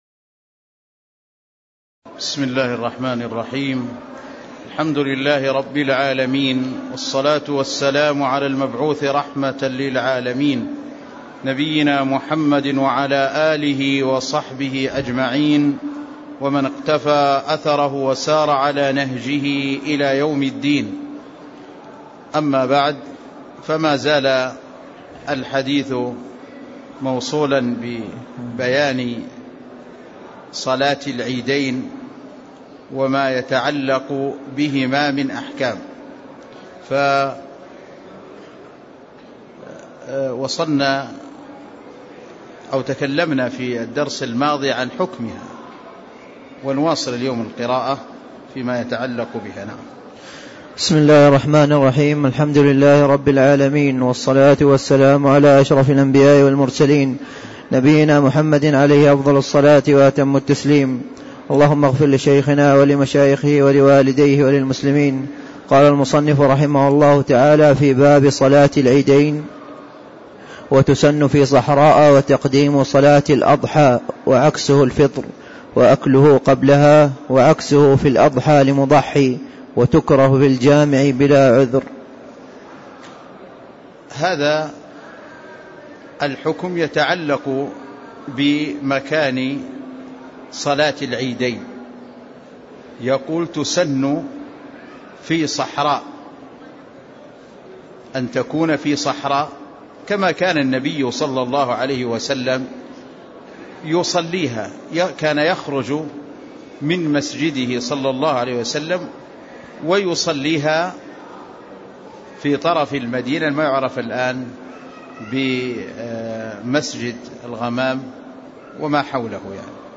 تاريخ النشر ٨ ربيع الأول ١٤٣٦ هـ المكان: المسجد النبوي الشيخ